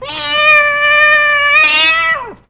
Cat
Cat.wav